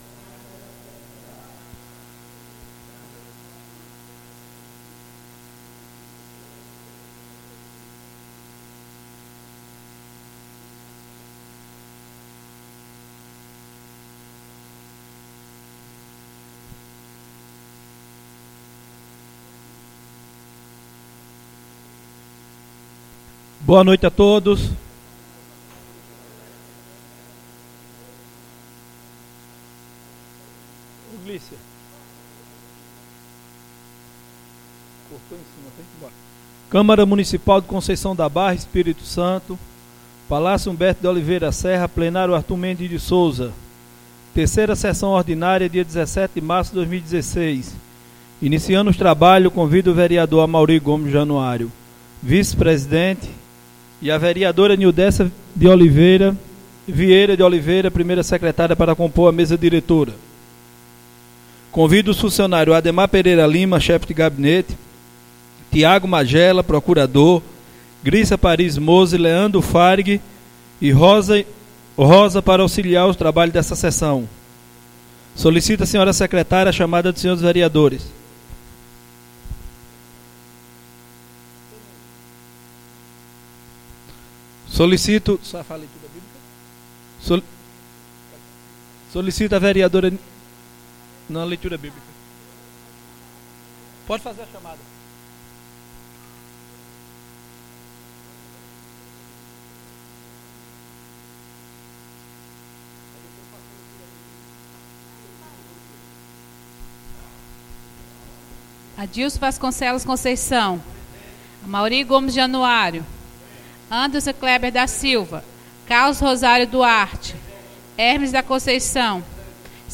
3ª SESSÃO ORDINÁRIA